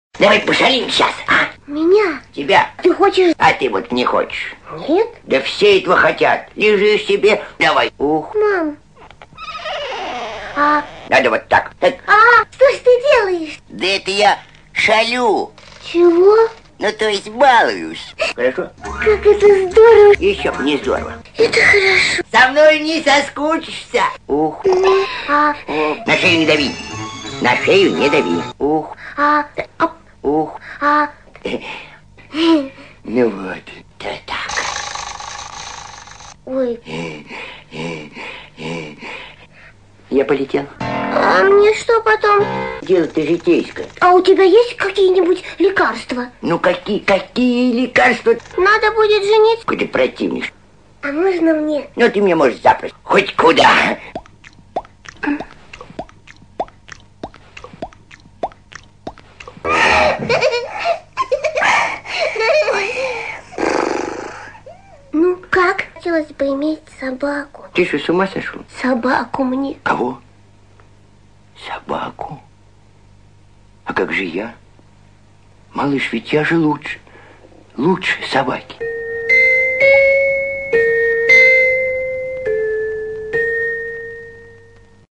Прикольная нарезка из известного мульта.